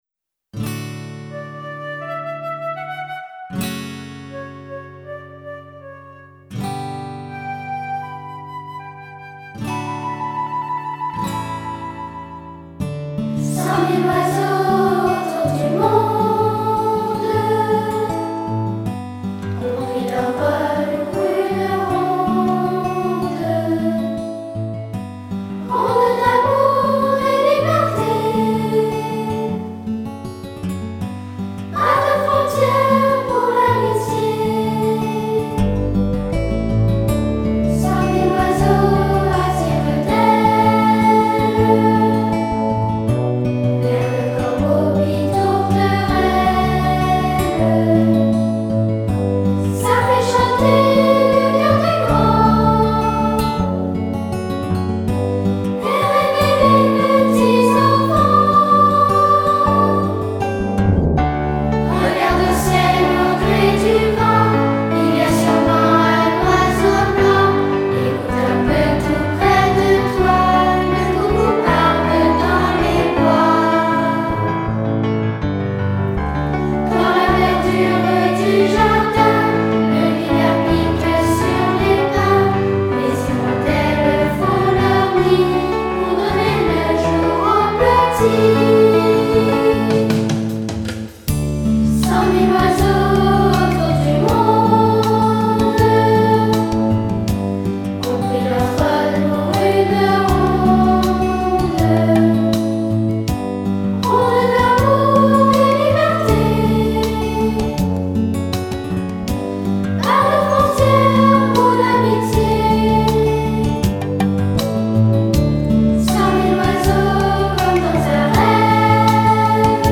Version chantée :